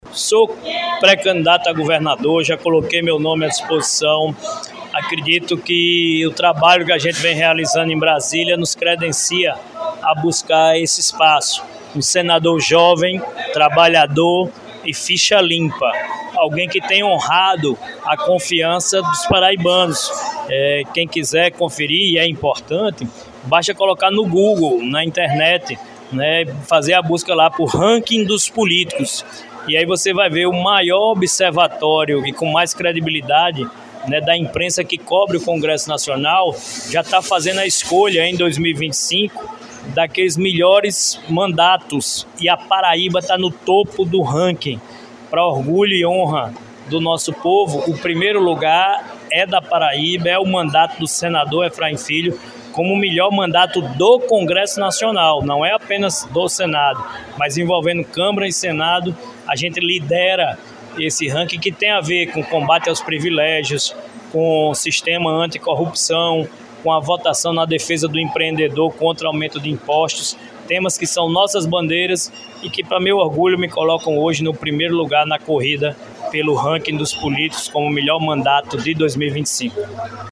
Durante as comemorações pelos 190 anos de emancipação política de Catolé do Rocha, realizadas no último domingo (25), o senador Efraim Filho (União Brasil) marcou presença e, em entrevista à imprensa local, fez importantes declarações sobre o cenário político estadual.
Ainda durante a entrevista, Efraim Filho não hesitou em anunciar sua pré-candidatura ao Governo do Estado.
03-Senador-Efraim-Filho-Pre-candidato-ao-governo-do-Estado.mp3